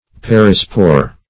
perispore - definition of perispore - synonyms, pronunciation, spelling from Free Dictionary Search Result for " perispore" : The Collaborative International Dictionary of English v.0.48: Perispore \Per"i*spore\, n. (Bot.) The outer covering of a spore.